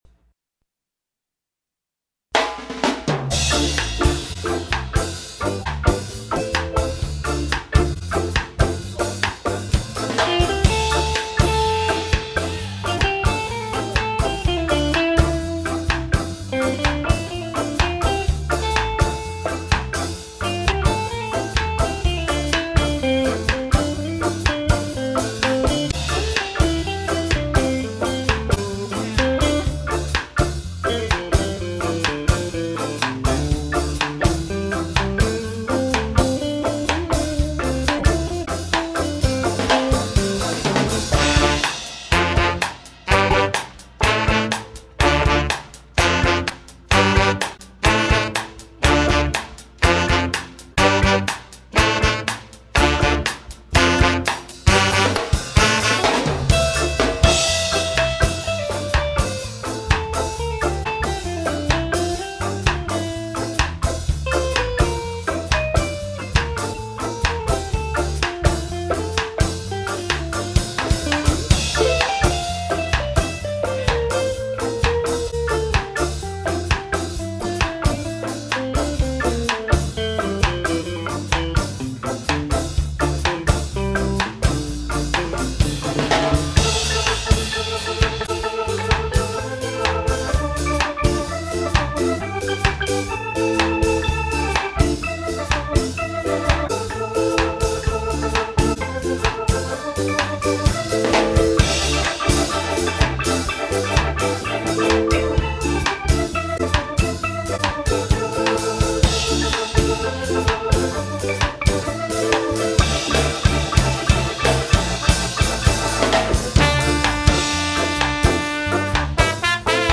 Recorded live at 70Hurtz studio in Argyle, TX 1996